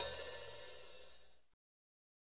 HAT A C OH0I.wav